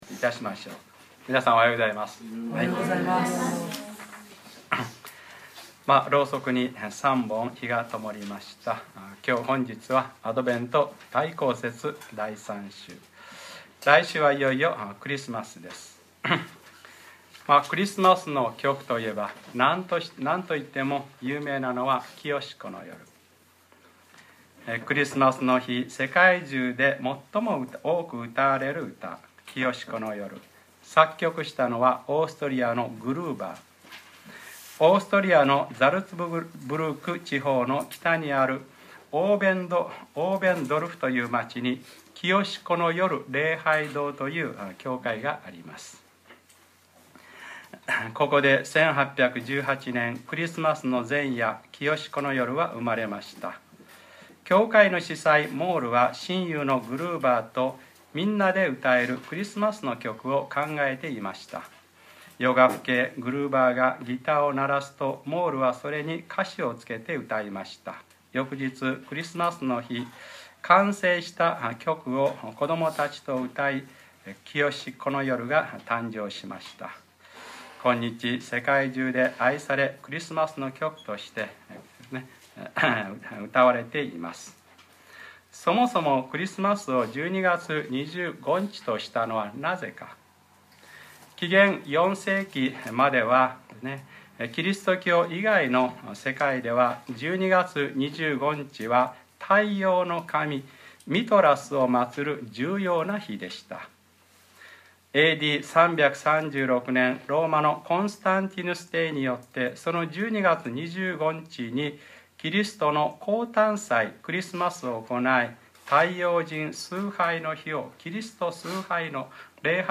2012年12月16日(日）礼拝説教 『黙示録15/ 腹には苦いが口には蜜のように甘い』